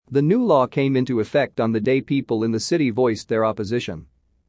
このままの速度でお聞きください。
【ノーマル・スピード】
voice(d) の語尾の d はほぼ脱落するため、現在形と同様に聞こえる